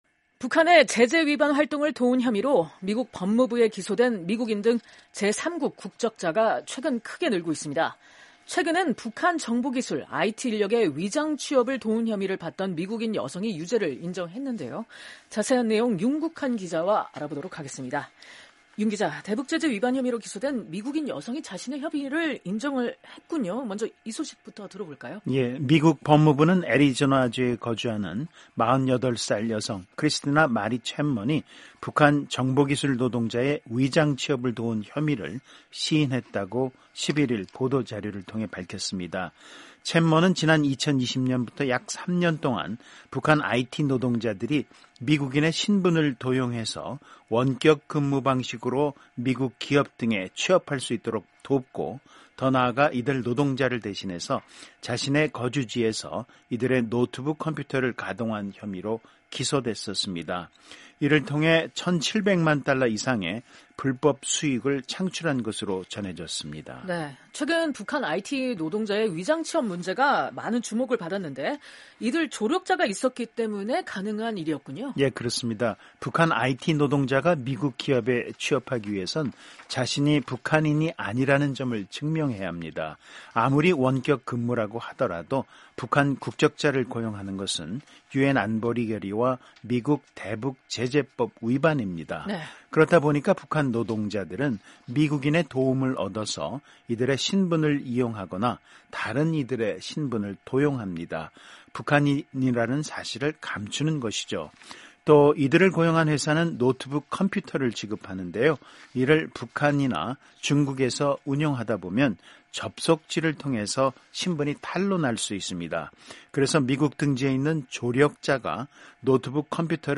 [기자문답] 대북제재 위반 ‘조력자’ 기소 급증…지난 1년간 9명